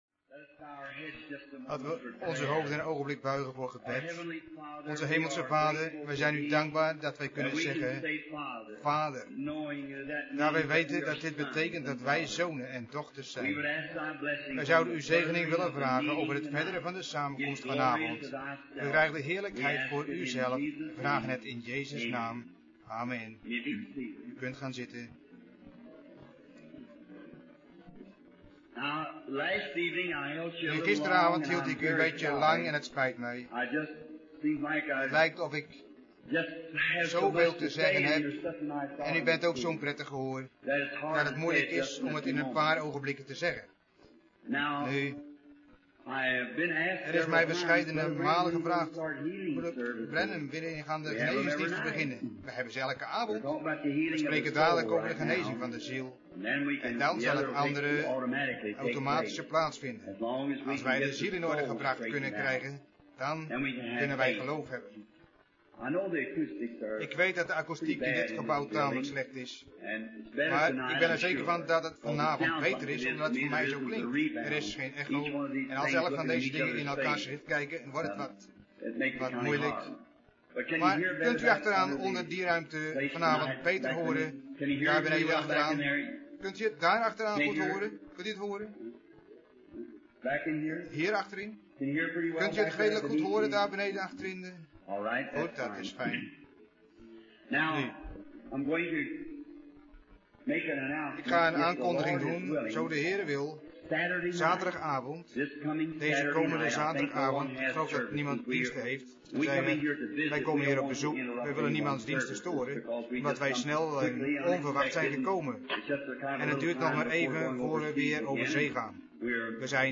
Vertaalde prediking "Former and latter rain" door W.M. Branham te Madison square garden, Phoenix, Arizona, USA, 's avonds op donderdag 03 maart 1960